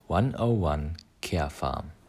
Mit unserem neuen Namen 1 0 1 Carefarm - aus dem Englischen abgeleitet mit der Aussprache "
One O One Carefarm" - ändert sich nichts an unserem Anspruch, qualitativ hochwertige Original-Arzneimittel für Sie als Apotheke für kleines Geld zur Verfügung zu stellen.